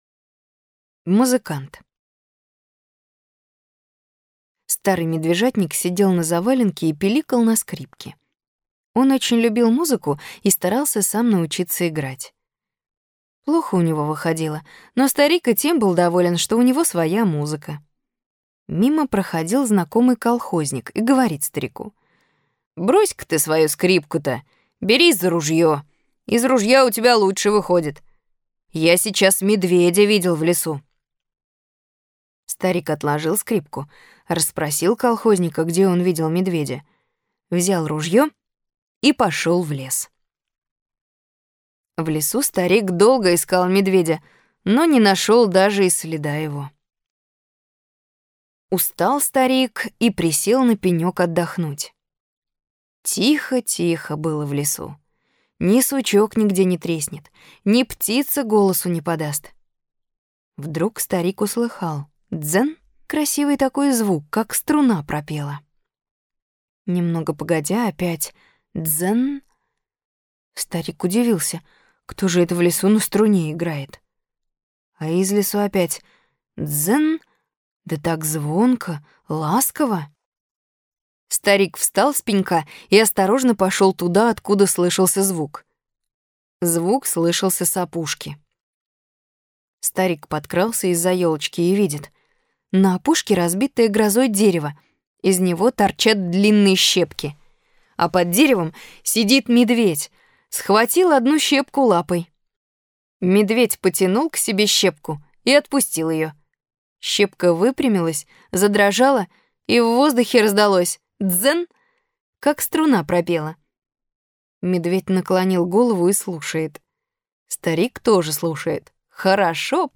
Музыкант - аудио рассказ Бианки В.В. Однажды старому медвежатнику сказали, что в лесу бродит медведь, он взял ружьё и пошел в лес.